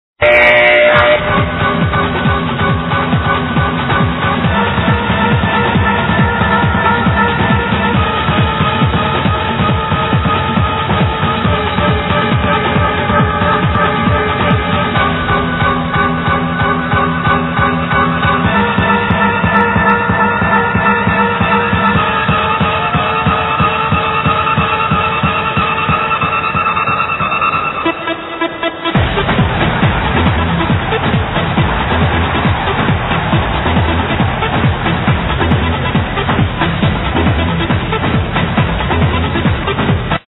no idea man.. pretty interesting sound tho